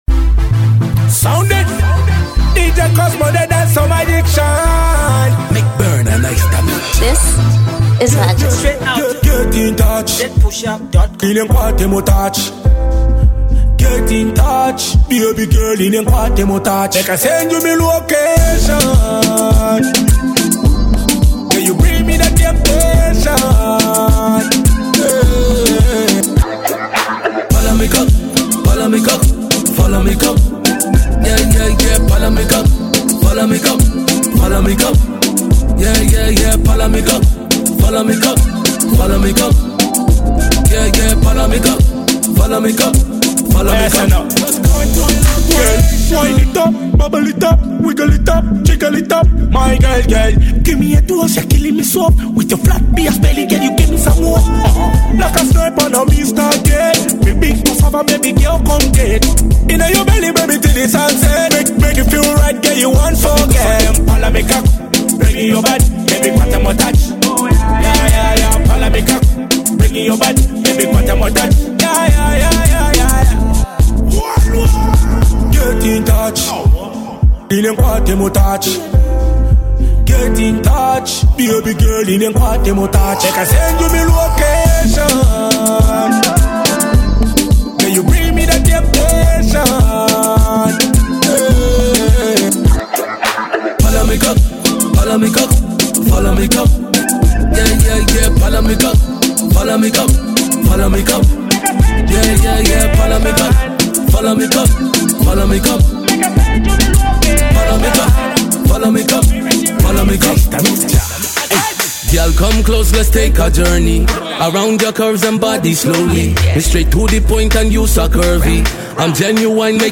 Dance-hall